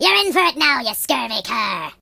project_files/HedgewarsMobile/Audio/Sounds/voices/Pirate/Youllregretthat.ogg
Youllregretthat.ogg